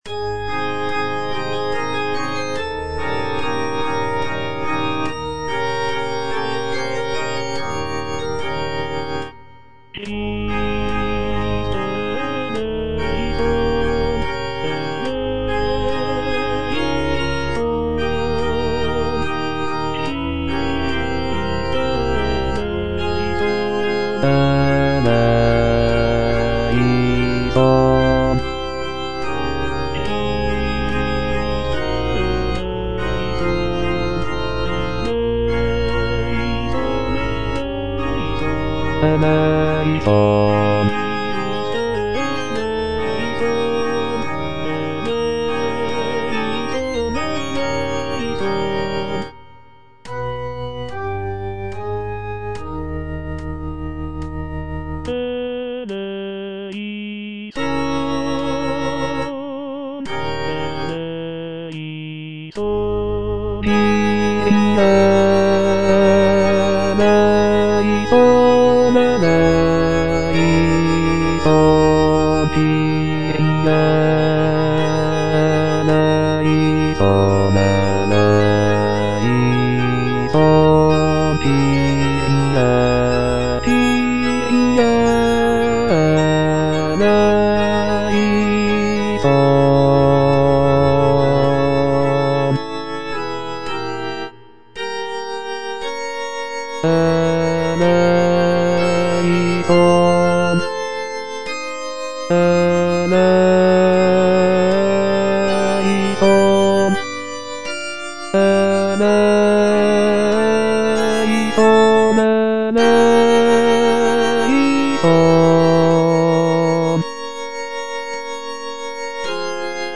C.M. VON WEBER - MISSA SANCTA NO.1 Christe eleison - Bass (Voice with metronome) Ads stop: auto-stop
The work features a grand and powerful sound, with rich harmonies and expressive melodies.